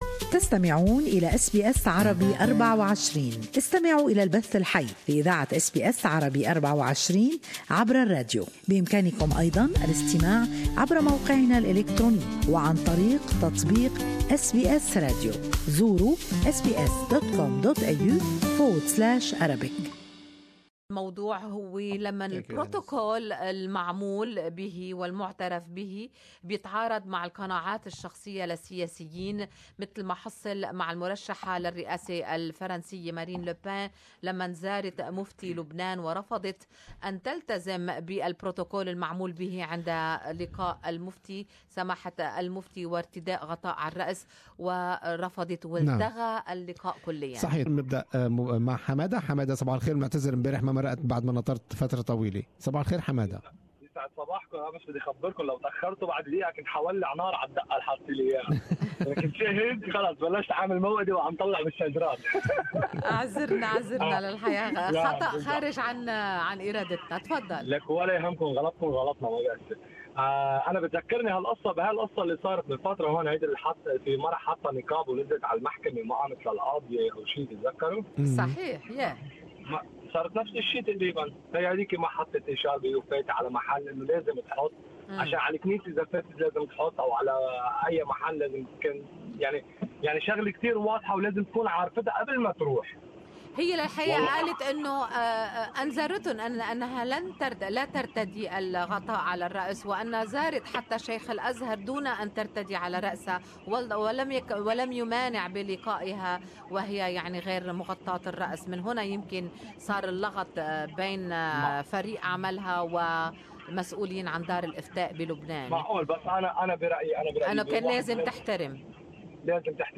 Good Morning Australia program discussed this topic with its listeners who had different opinions on the topic.